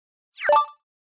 Звуки ужаса или для создания эффекта чего-то ужасного для монтажа видео
3. Эффект для ужаса
shrink-ray.mp3